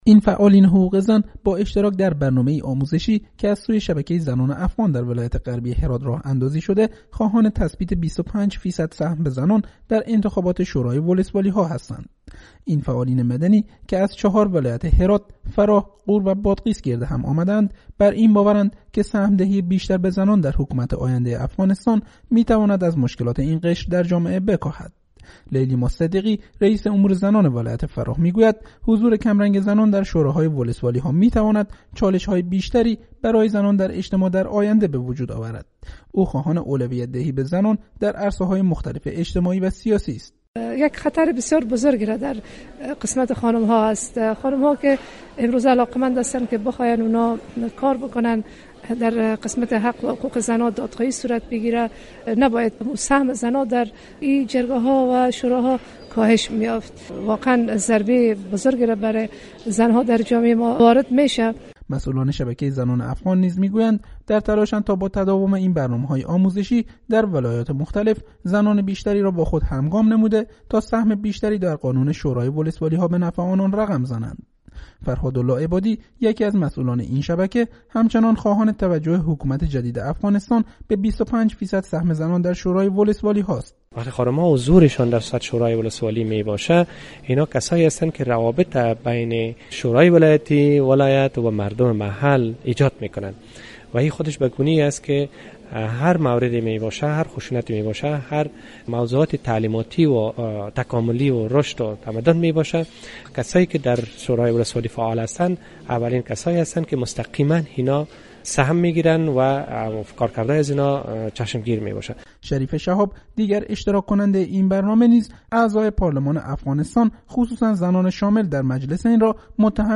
گزارش صوتی را در این مورد از پیوند زیر بشنوید: